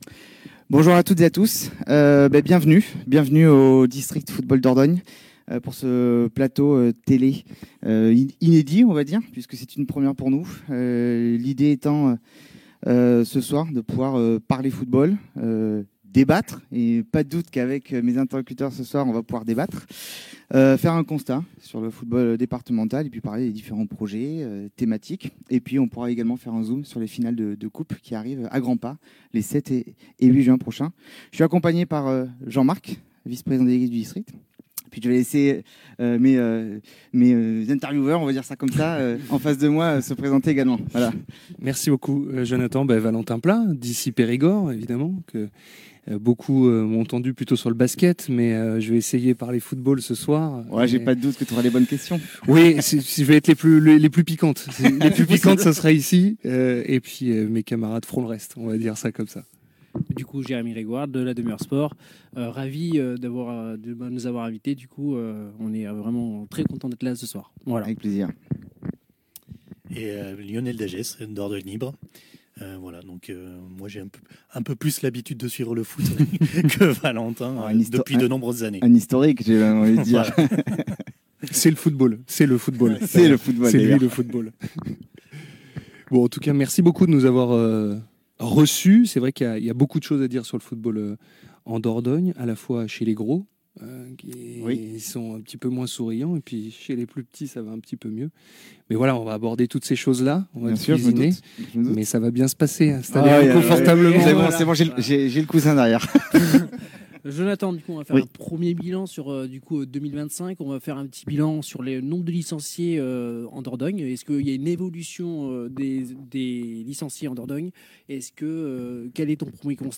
Trois journalistes, une même passion du sport et un invité au cœur des enjeux locaux…